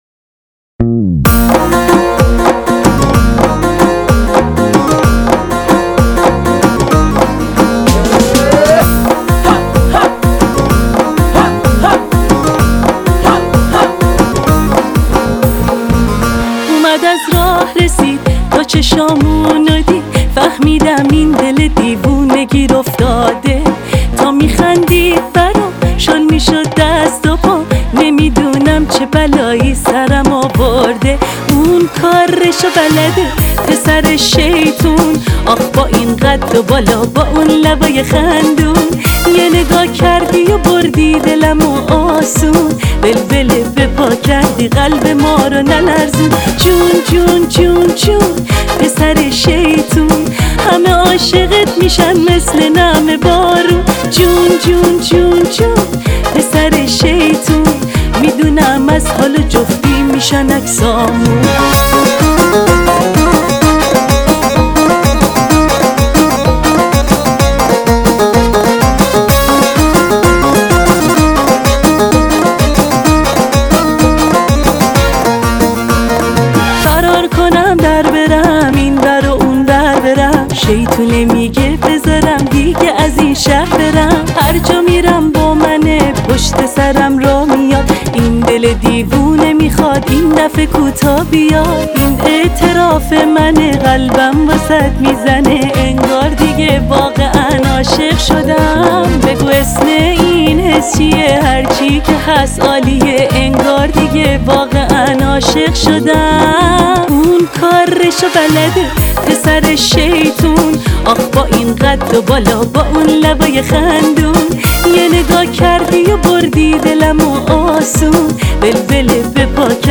یه آهنگ شاد شاد